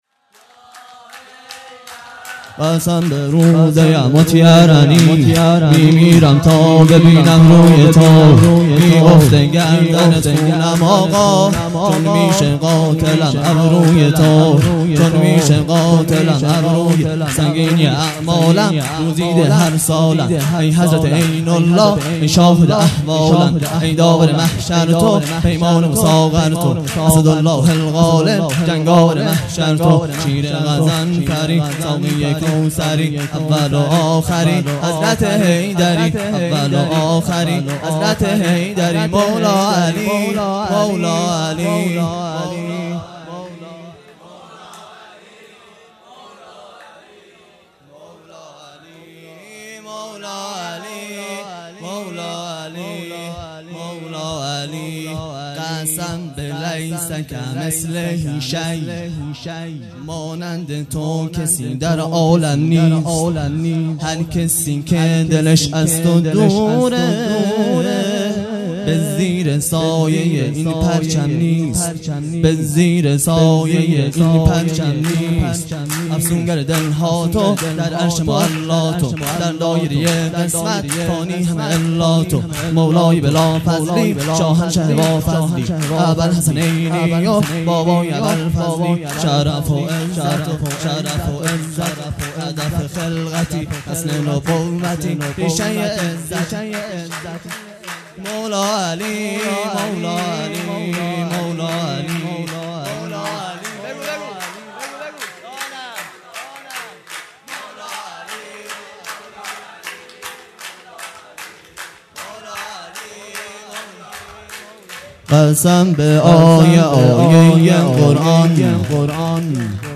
خیمه گاه - هیئت بچه های فاطمه (س) - سرود | قسم به روز یموت یرنی
جلسه هفتگی هیئت به مناسبت ولادت حضرت معصومه(س)